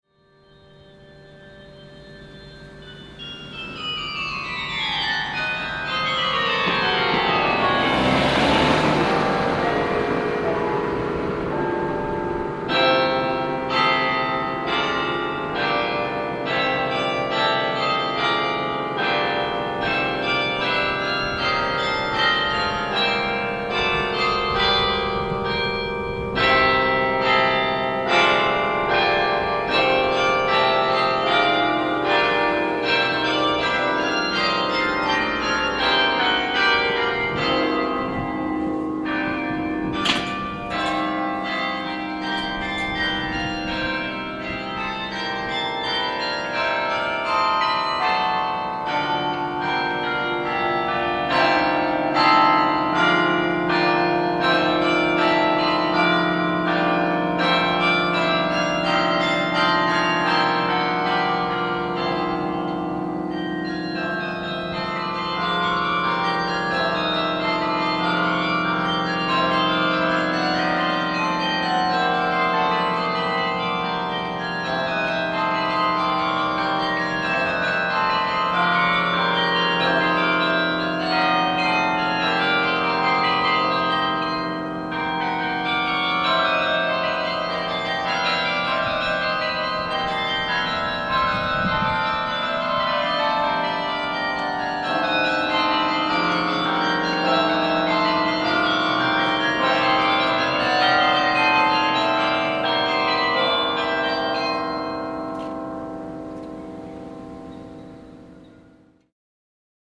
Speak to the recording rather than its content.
Carillon of Raadhuis, Hilversum, Netherlands